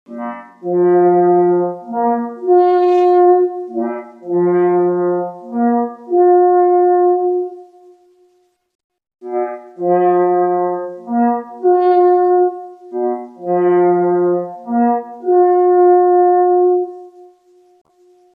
Sygnały mysliwskie
Sygnały łowieckie